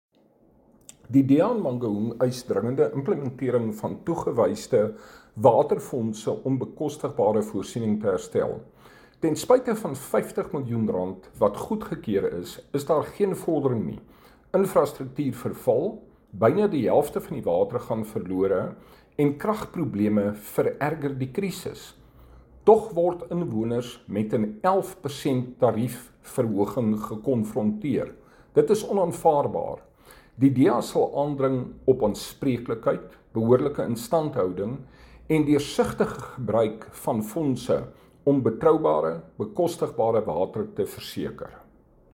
Afrikaans soundbites by Cllr Hardie Viviers and